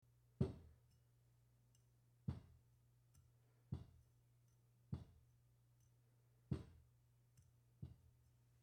Звуки скатерти